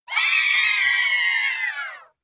yay.mp3